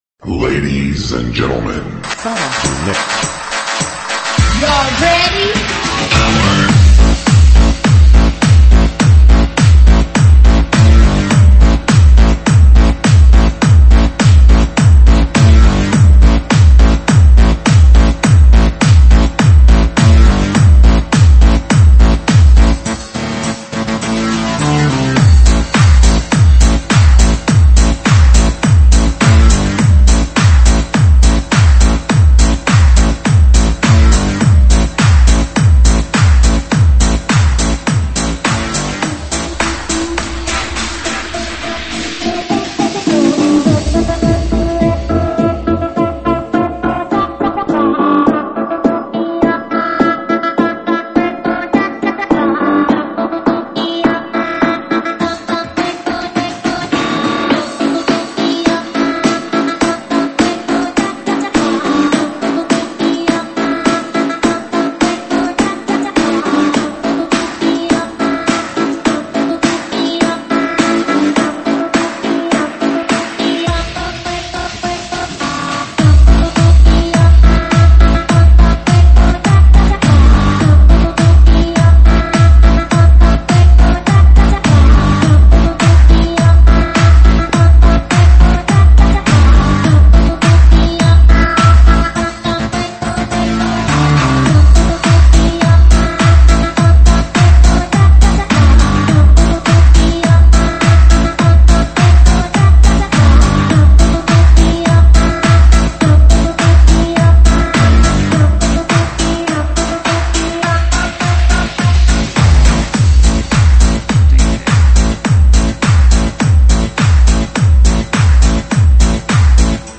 慢摇舞曲
舞曲类别：慢摇舞曲